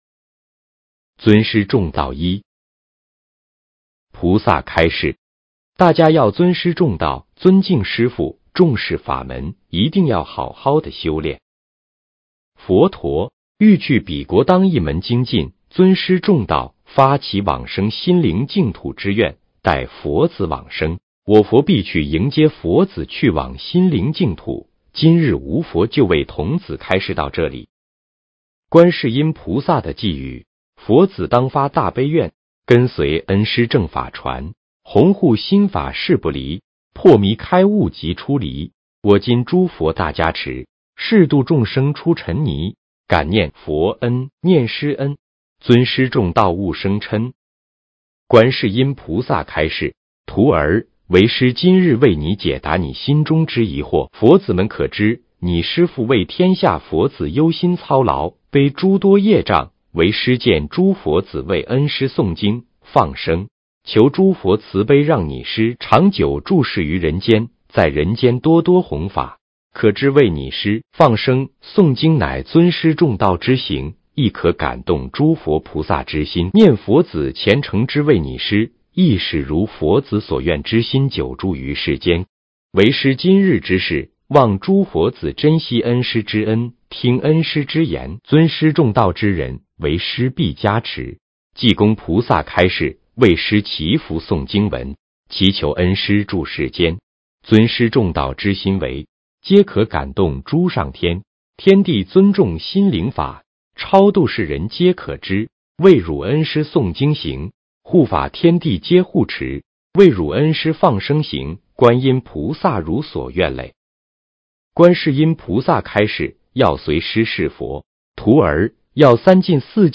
【尊师重道】开示合集 - 文字+录音+视频 - 开示精选及合集【师父原音音视频+图文】 慈爱心灵